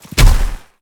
Sfx_creature_snowstalker_run_os_02.ogg